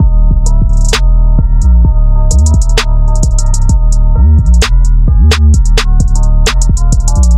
描述：用果味循环制作，这是鼓和钟的组合
Tag: 130 bpm Trap Loops Drum Loops 1.24 MB wav Key : E